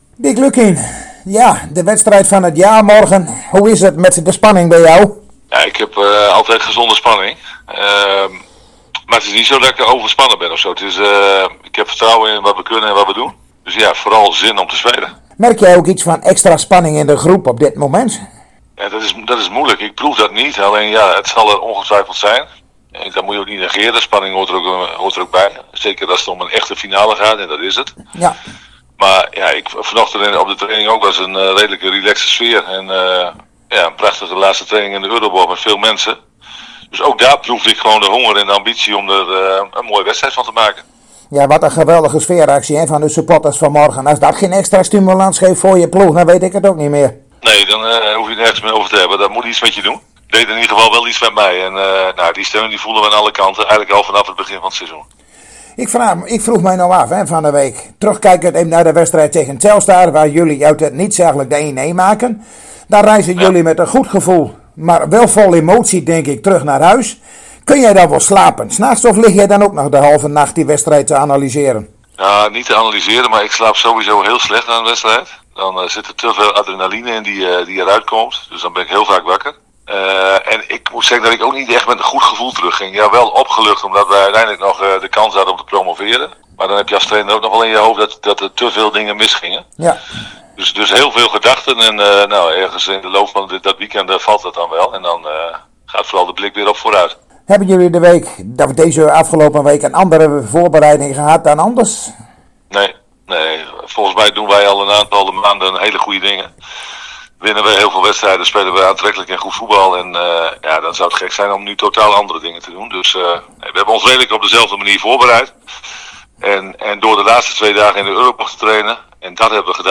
Zojuist spraken wij weer met trainer Dick Lukkien over de promotiekraker van morgenavond in de Euroborg tegen Roda JC, aftrap 20.00 uur.